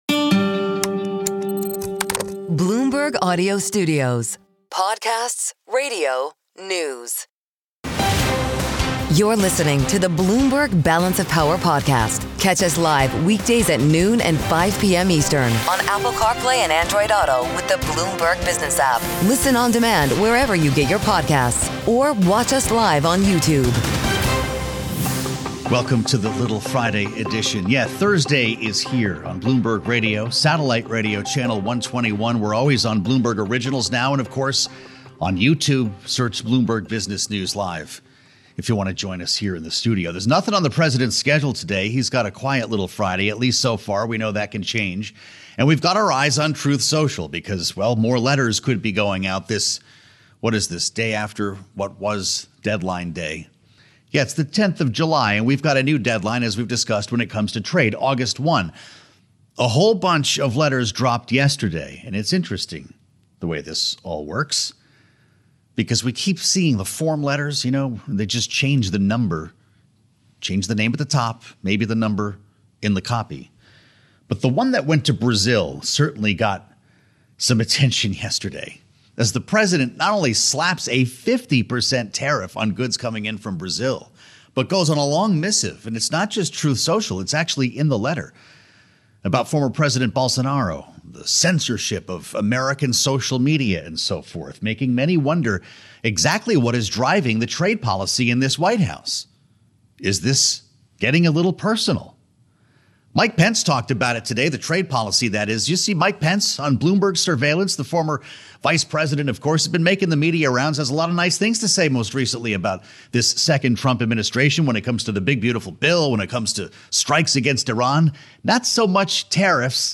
including conversations with influential lawmakers and key figures in politics and policy. Former Vice President Mike Pence criticized Donald Trump’s tariff agenda, saying it was a starkly different approach to trade from his first term that would harm American consumers and businesses.